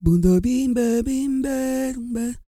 E-CROON 3002.wav